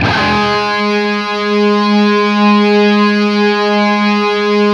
LEAD G#2 LP.wav